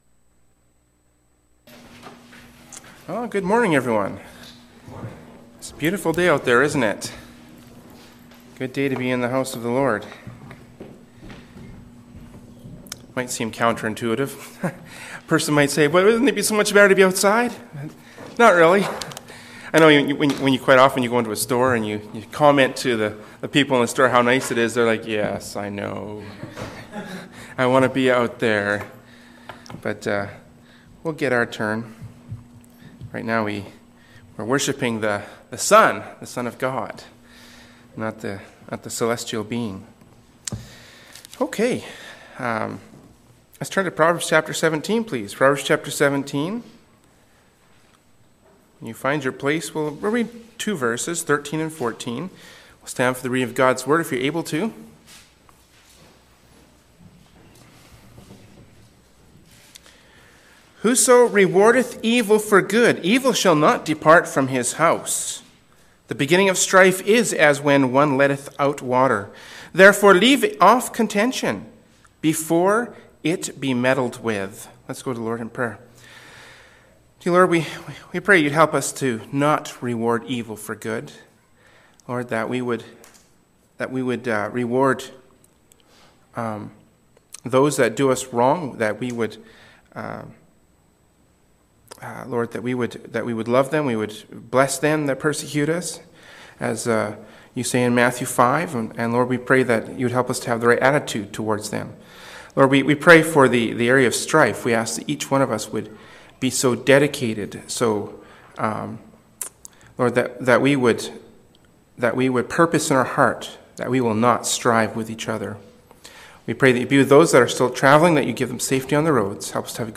“Proverbs 17:13-14” from Sunday School Service by Berean Baptist Church.